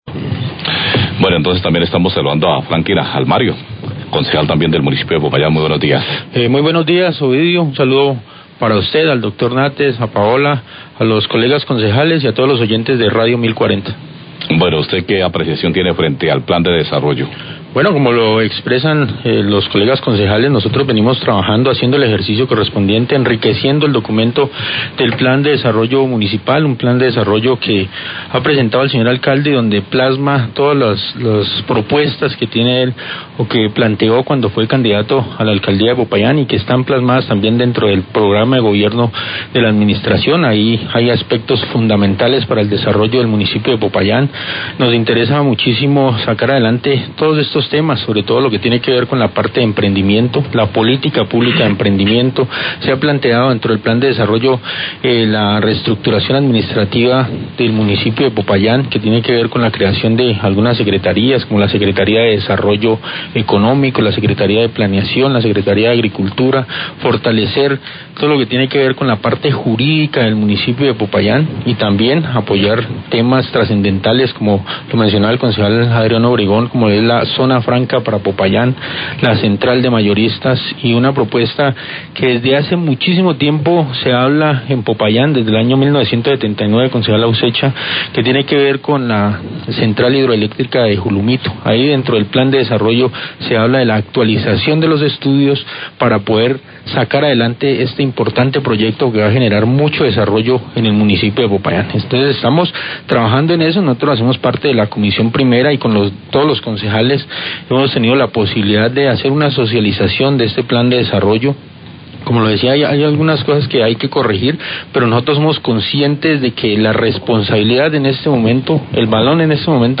Radio
Los Concejales de Popayán, Franklin Almario y Julian Ausecha, hablan del proyecto de la Central Hidroeléctrica Julumito, el proyecto tiene viabilidad, la actualización de los estudios con esto ya habrá varios proponentes, va a generar mucho desarrollo en Popayán, y se podrá vender energía eléctrica.